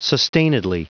Prononciation du mot sustainedly en anglais (fichier audio)
Prononciation du mot : sustainedly